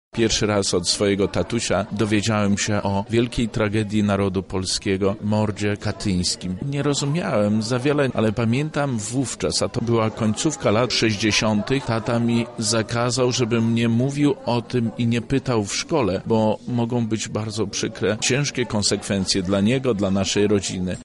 Jubileusz 30-lecia przeżywa Rodzina Katyńska. Okrągłe obchody odbyły się w siedzibie lubelskiego IPN-u.
O początkach poznawania przez siebie historii mówi wicemarszałek województwa Zbigniew Wojciechowski.